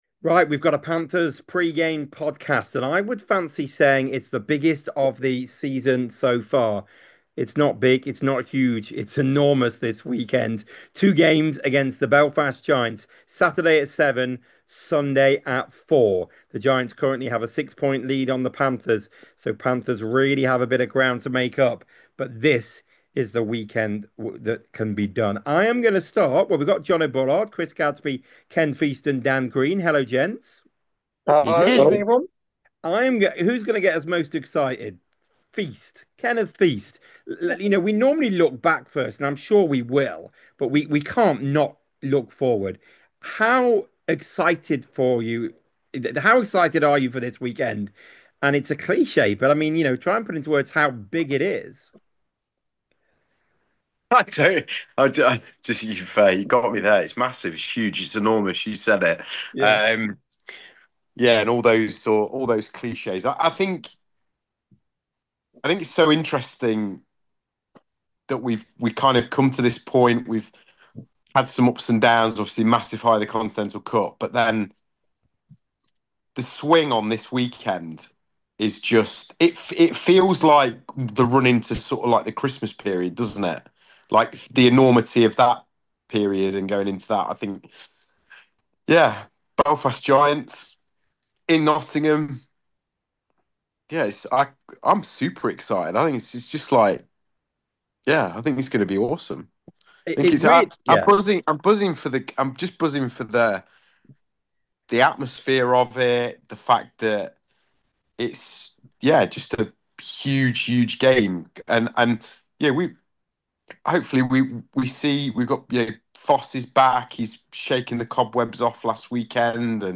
The five guys look ahead to the massive weekend and reflect on the past seven days. There's also a heated debate about tempting fate with messages in WhatsApp groups!